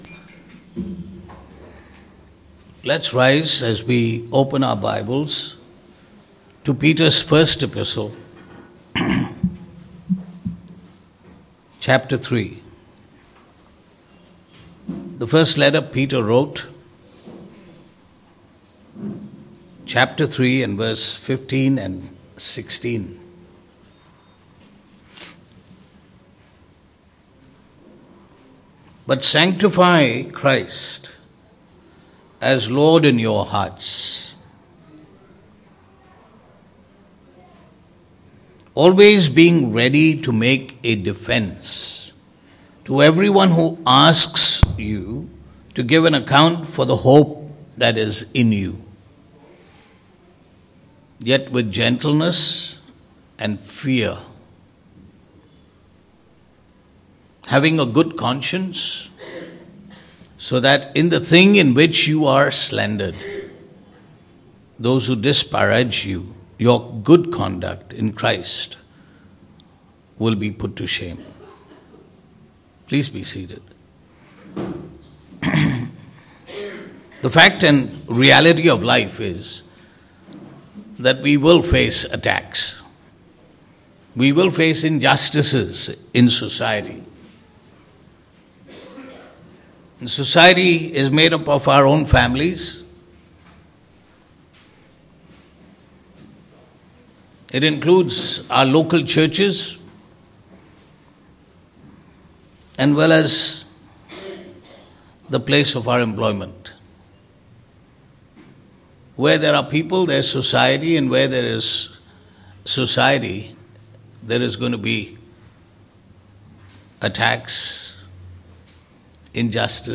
Passage: 1 Peter 3:15-16 Service Type: Sunday Morning